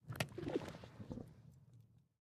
Minecraft Version Minecraft Version latest Latest Release | Latest Snapshot latest / assets / minecraft / sounds / block / trial_spawner / ambient3.ogg Compare With Compare With Latest Release | Latest Snapshot